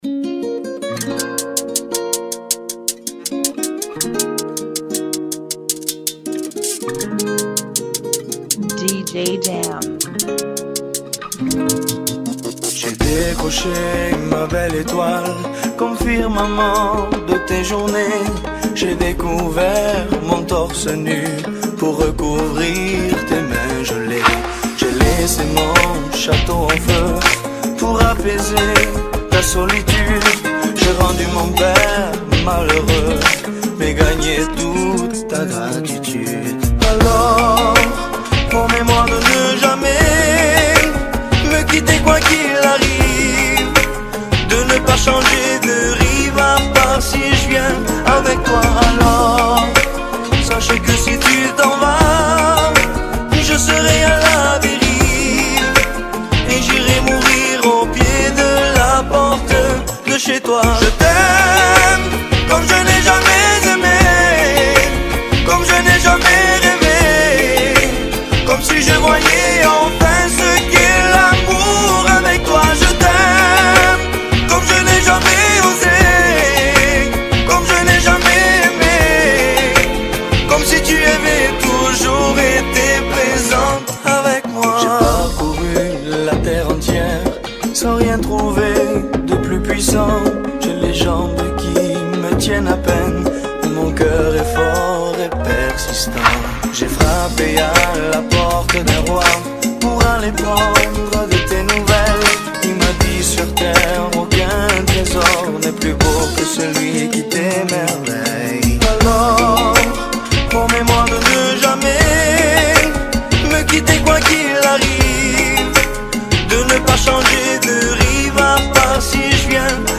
160 BPM
Genre: Salsa Remix